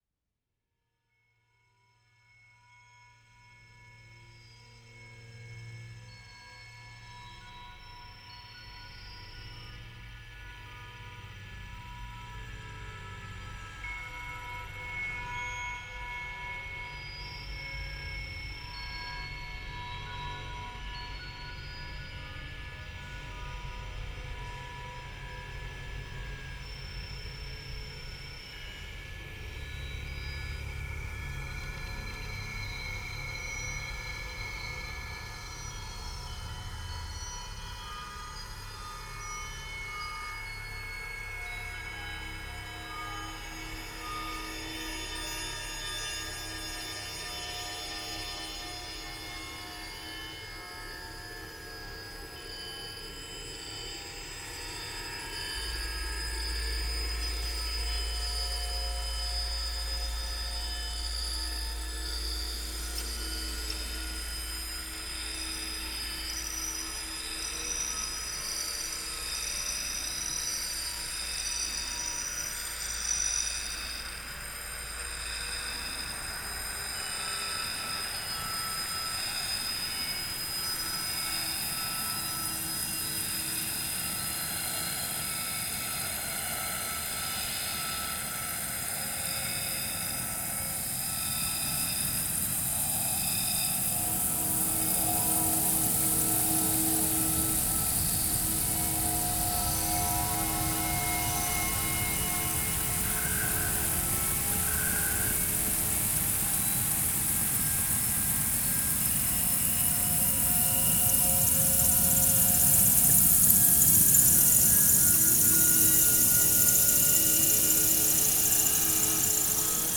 This work is a world built from cicada recordings collected across various physical and digital locations.
Through layering, I sought to create a realm of absence and presence—mourning lost landscapes and invoking their return. The voices of cicadas merge with fragments of memory, forming a speculative ecosystem.